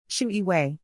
first-name-pronunciation.mp3